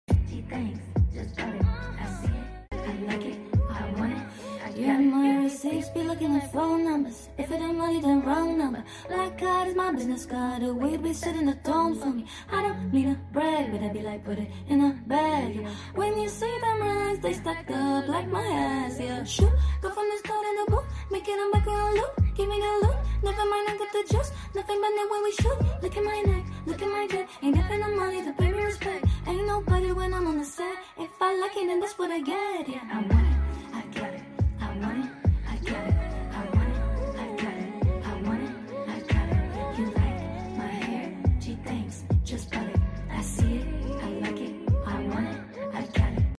vocal cover